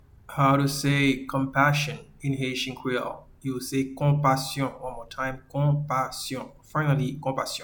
Pronunciation:
Compassion-in-Haitian-Creole-Konpasyon.mp3